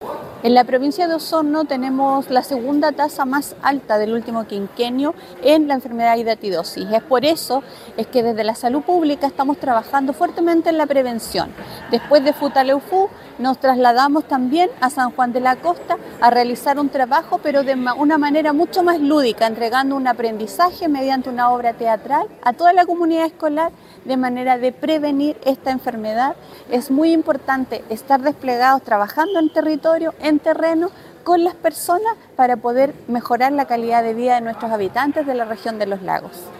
La seremi de Salud regional, Karin Solís Hinojosa, destacó el trabajo territorial que se ha estado realizando en la región para la prevención de la hidatidosis, pues en la provincia de Osorno tenemos la segunda tasa más alta del último quinquenio.